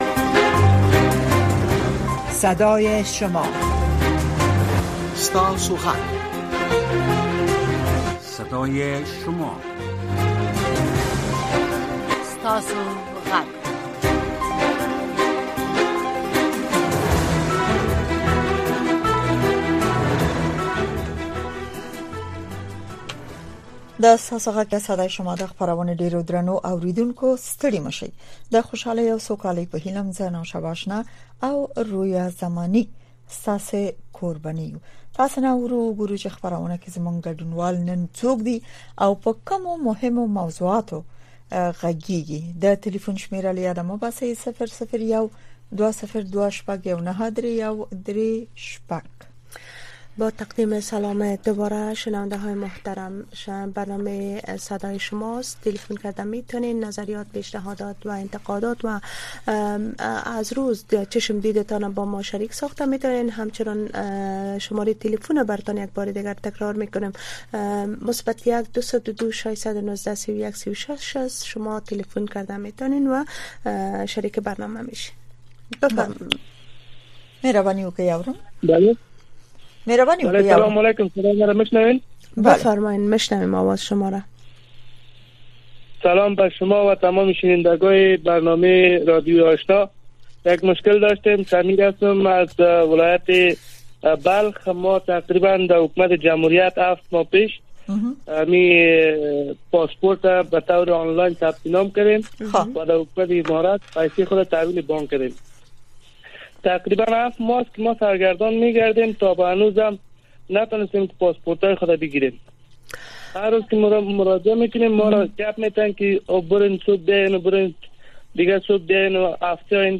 در برنامۀ صدای شما شنوندگان رادیو آشنا صدای امریکا به گونۀ مستقیم با ما به تماس شده و نظریات، نگرانی‌ها، دیدگاه، انتقادات و شکایات شان را با گردانندگان و شنوندگان این برنامه در میان می‌گذارند. این برنامه به گونۀ زنده از ساعت ۱۰:۰۰ تا ۱۰:۳۰ شب به وقت افغانستان نشر می‌شود.